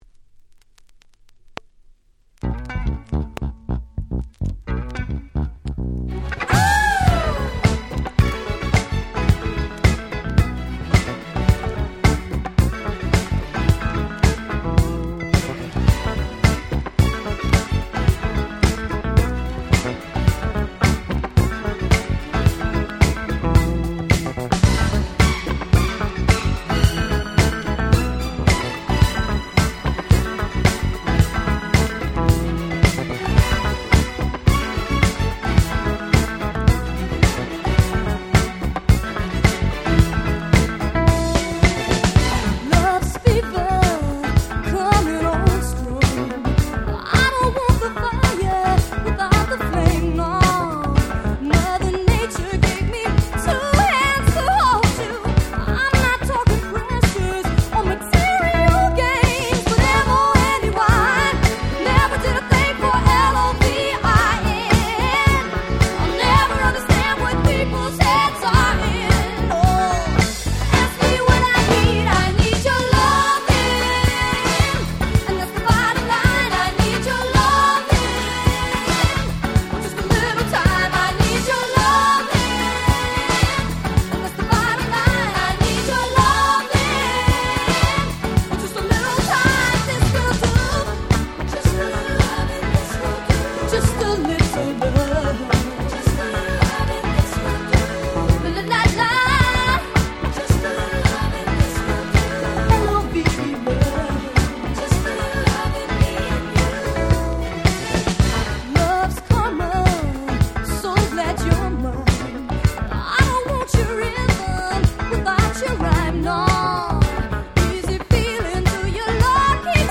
Disco Boogieド定番！！